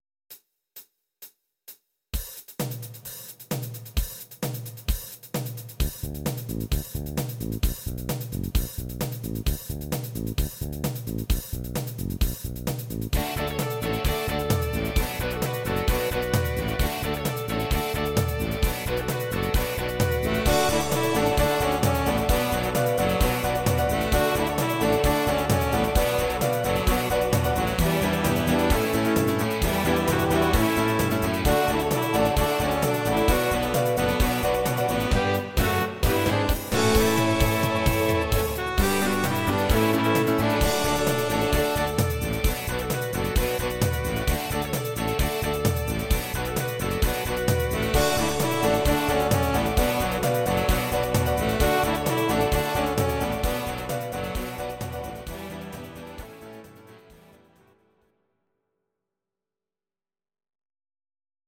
Audio Recordings based on Midi-files
Pop, Oldies, Medleys